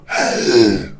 spawners_mobs_uruk_hai_hit.1.ogg